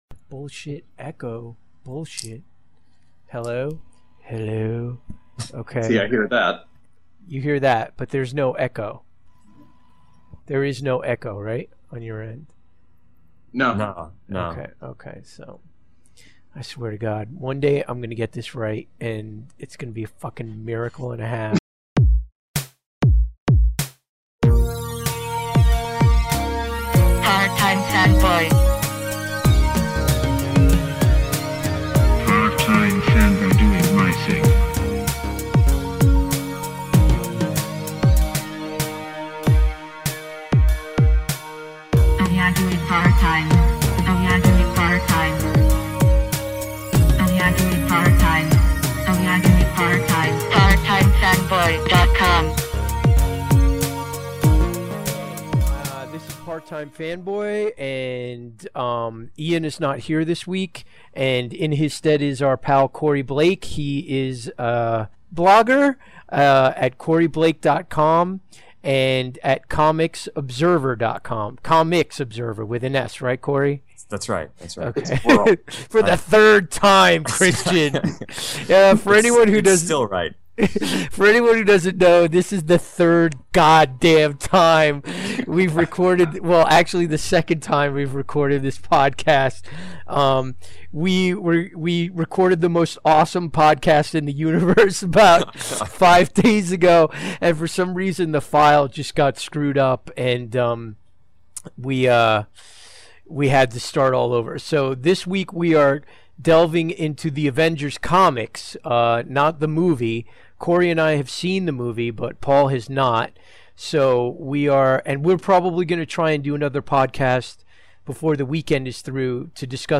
The Part-Time Fanboy crew decides to sit down and discuss some of the highlights of the last almost fifty (!) years of Avengers comics.